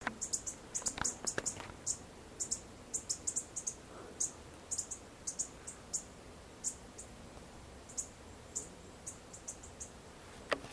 Frutero Yungueño (Chlorospingus flavopectus)
Fase de la vida: Adulto
Localidad o área protegida: Ruta 9 E/ Salta y Jujuy
Condición: Silvestre
Certeza: Observada, Vocalización Grabada